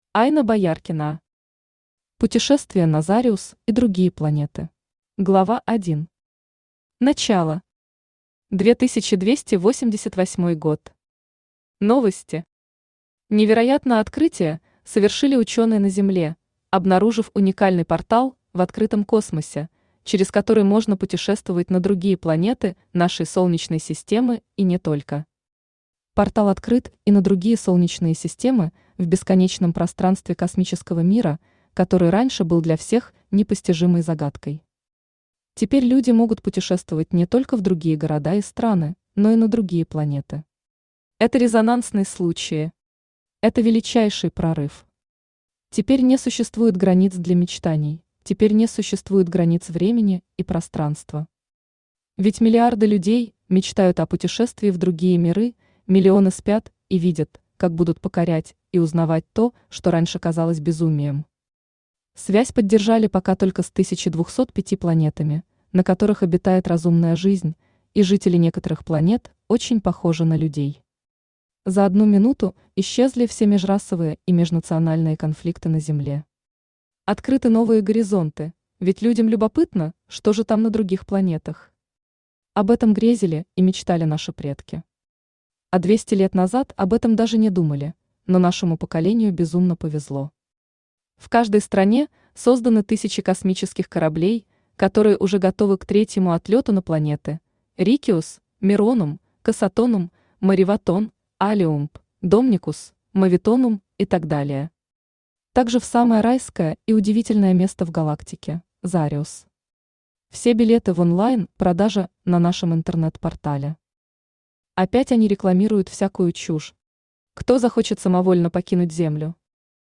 Аудиокнига Путешествие на Зариус и другие планеты | Библиотека аудиокниг
Aудиокнига Путешествие на Зариус и другие планеты Автор Айна Бояркина Читает аудиокнигу Авточтец ЛитРес.